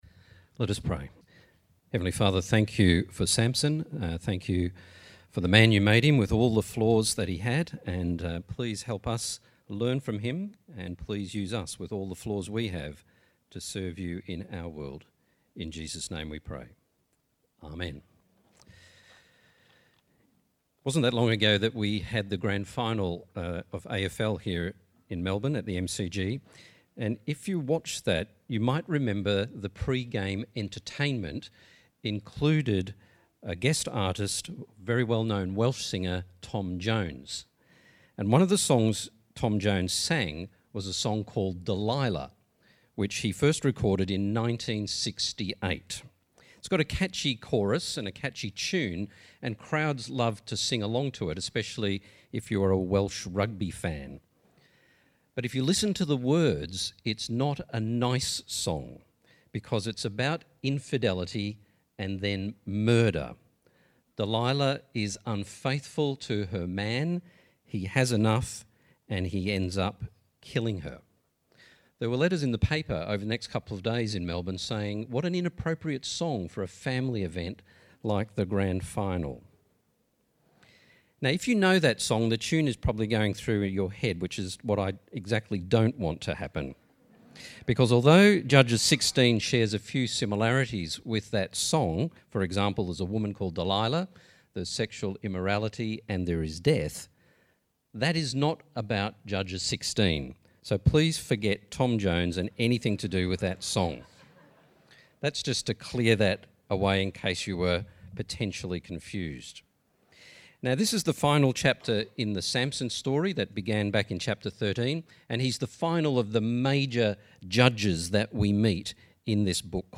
The Bible reading is Judges 16.